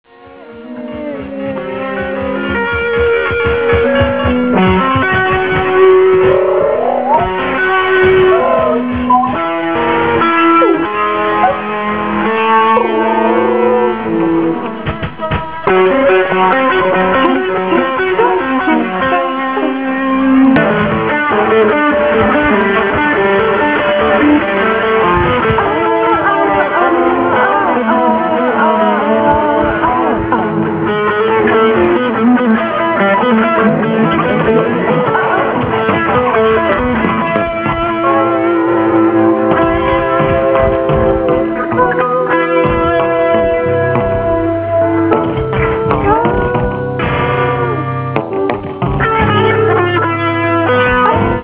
Cassette de soixante minutes
Poésie et musique improvisée
Prise de son, mixage au Studio Créason.